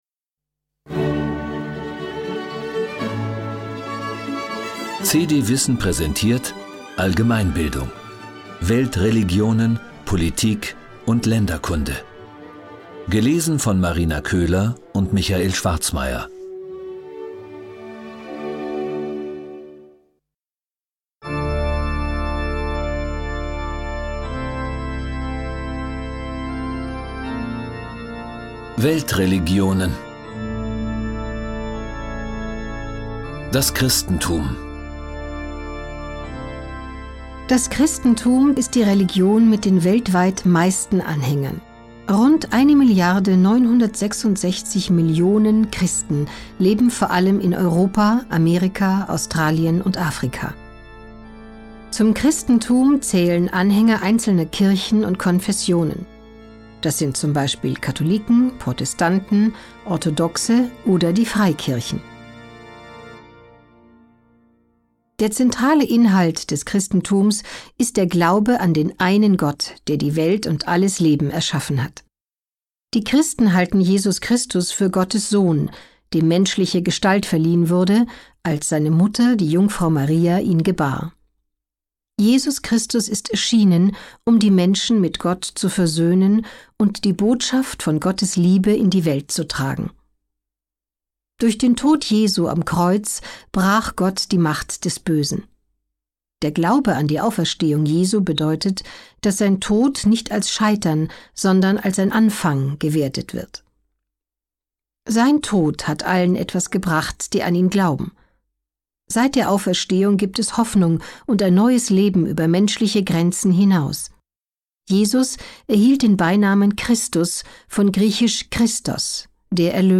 Hörbuch: CD WISSEN - Allgemeinbildung.